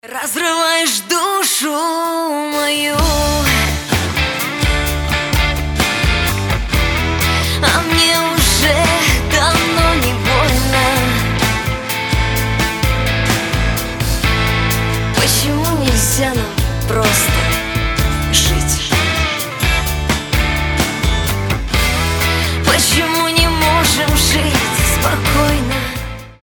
• Качество: 320, Stereo
гитара
женский вокал
душевные
грустные
электрогитара
поп-рок